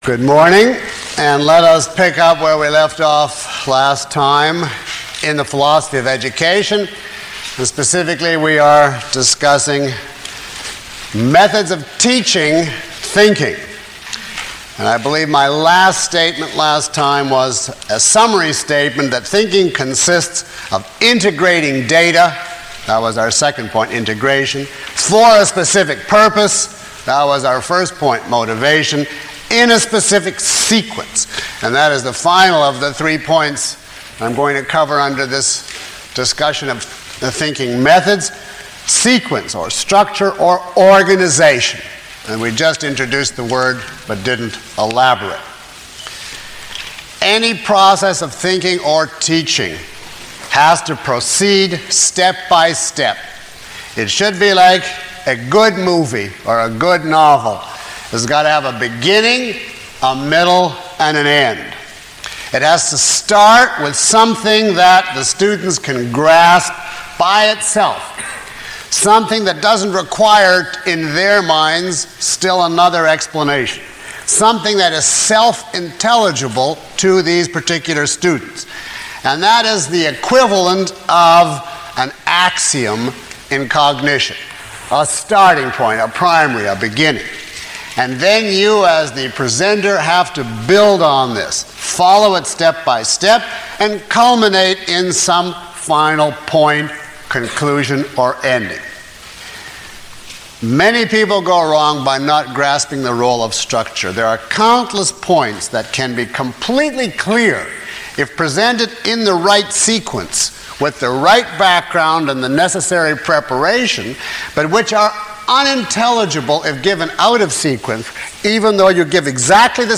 Lecture 03 - Philosophy of Education.mp3